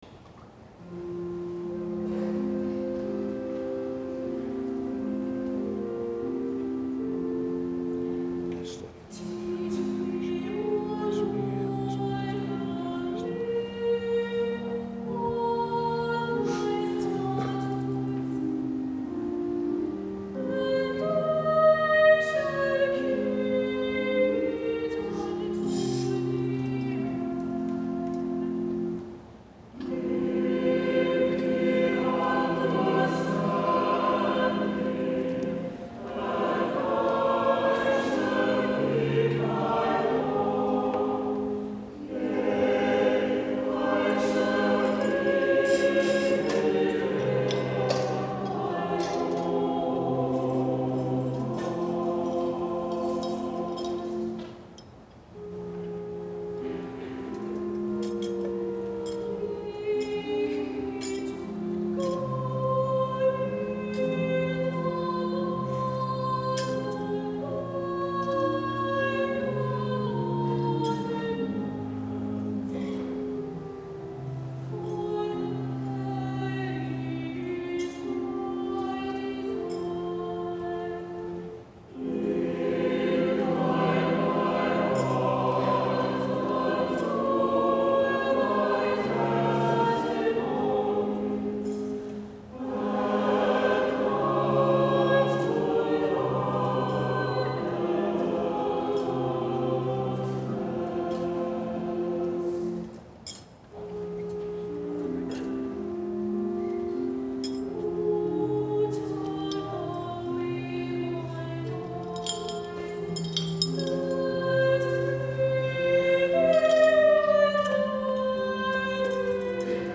Teach Me O Lord (Adult Choir)